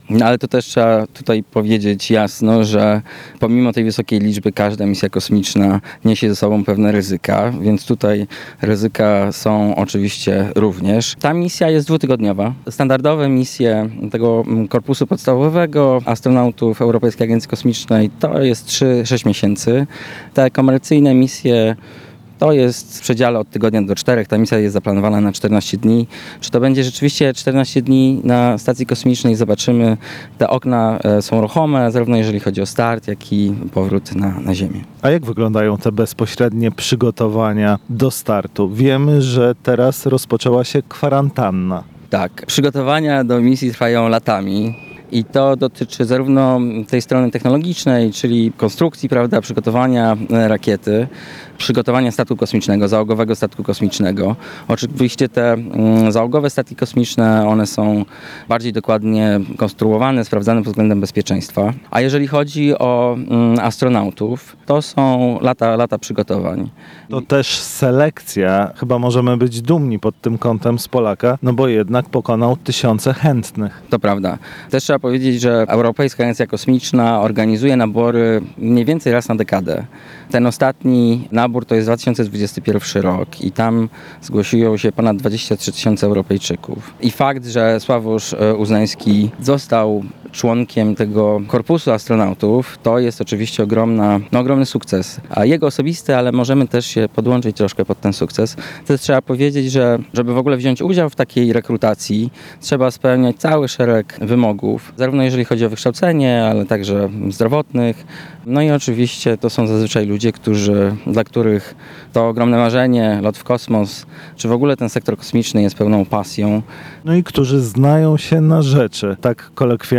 w rozmowie z naszym reporterem